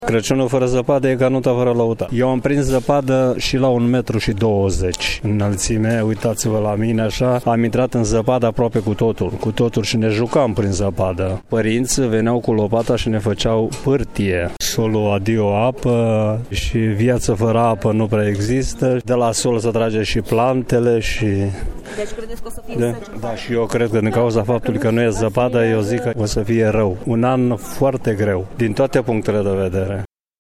VOX-.mp3